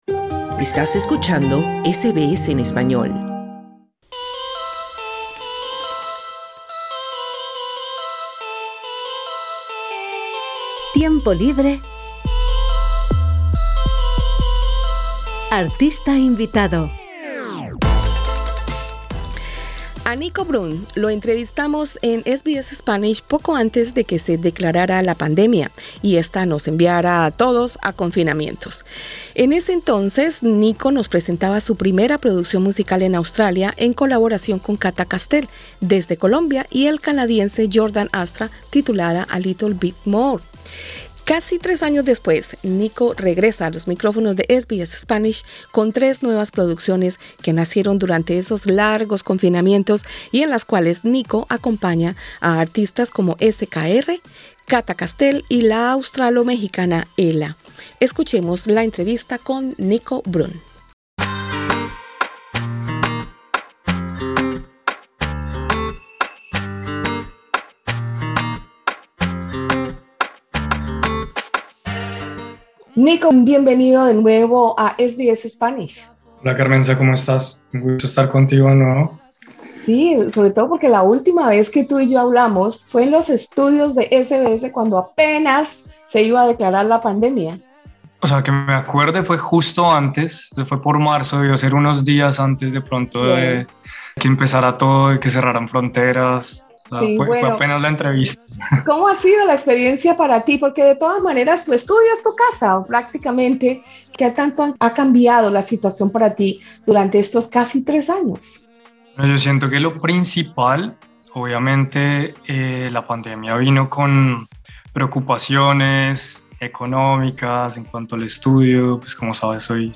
El compositor y productor musical